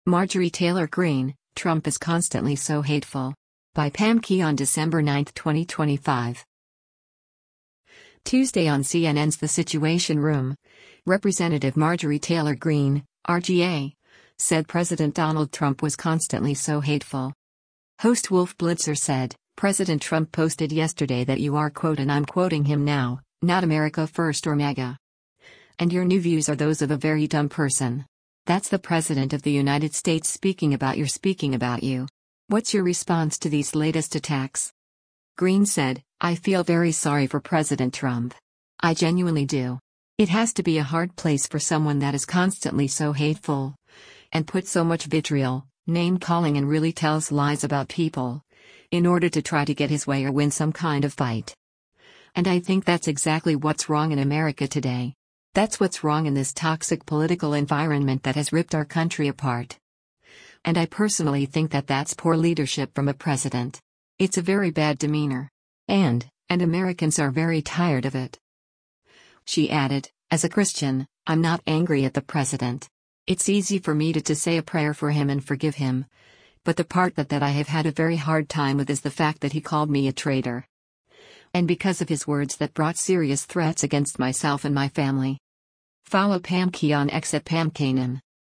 Tuesday on CNN’s “The Situation Room,” Rep. Marjorie Taylor Greene (R-GA) said President Donald Trump was “constantly so hateful.”